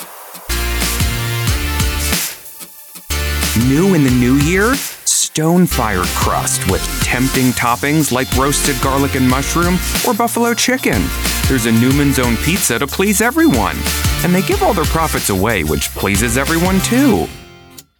Middle Aged
My reads land with confidence and playfulness.